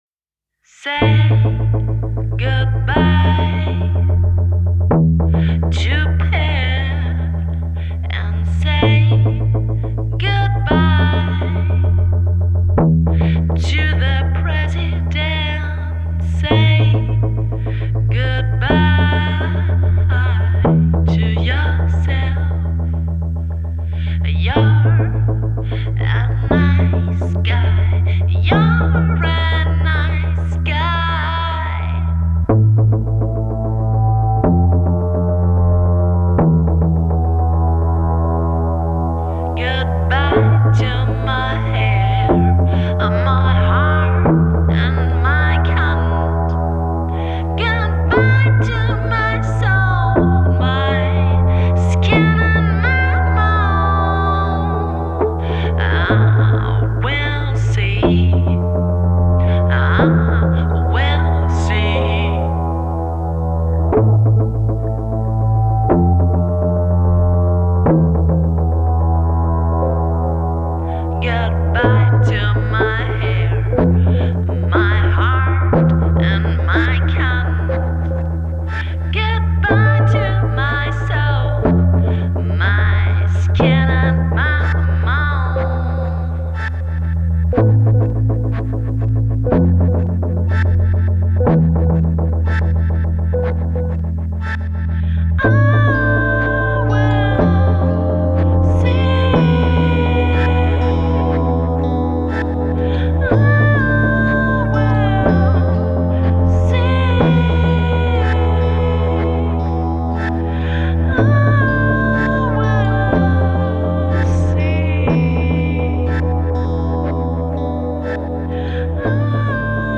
Electronic Music Solo Project since 2003